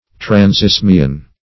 Search Result for " transisthmian" : The Collaborative International Dictionary of English v.0.48: Transisthmian \Trans*isth"mi*an\, a. Extending across an isthmus, as at Suez or Panama.